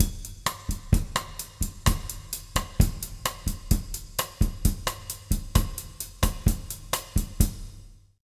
130BOSSA05-L.wav